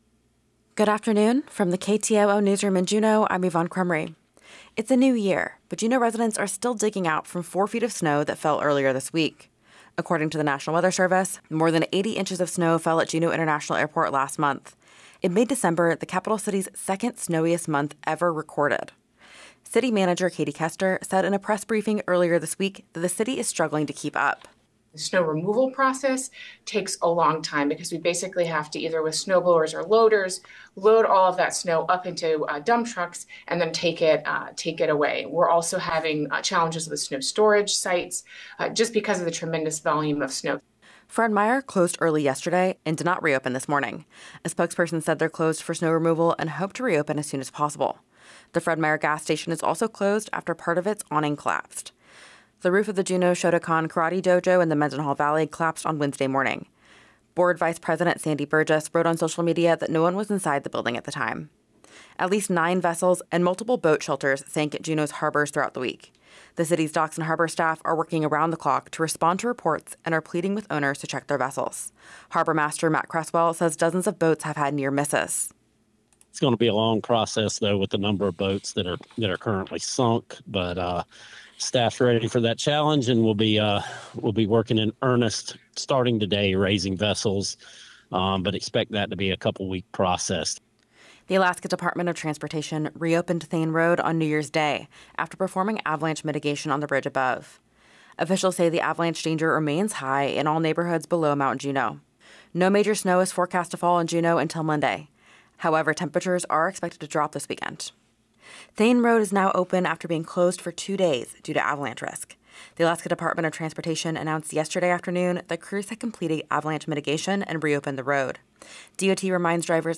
Newscast – Friday, Jan. 2, 2026 - Areyoupop